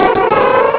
sovereignx/sound/direct_sound_samples/cries/illumise.aif at master